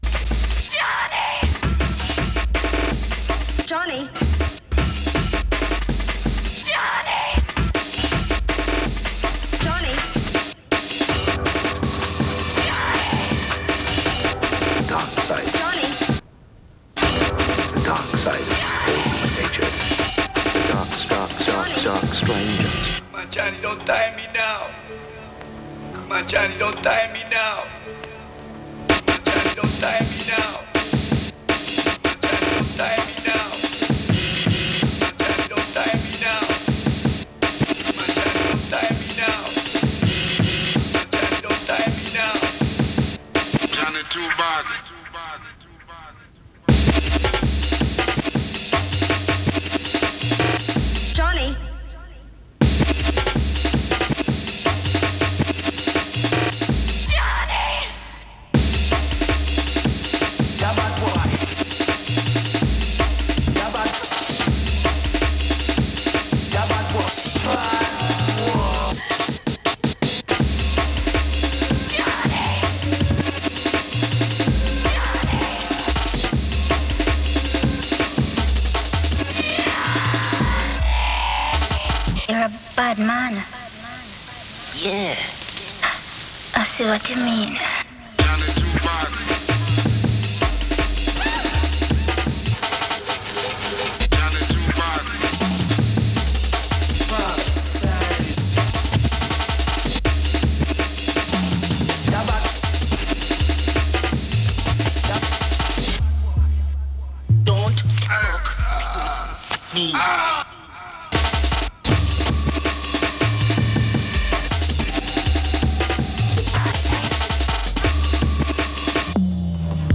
Stream the Jungle Tape Audio